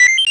pda_news.ogg.sfap0